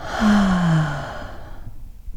SIGH 3  LOOP.wav